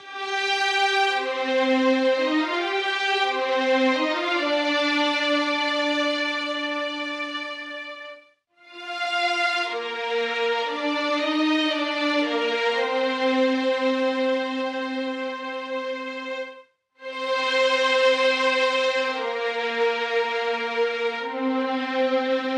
这是钢琴翻拍的形式